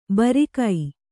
♪ barikai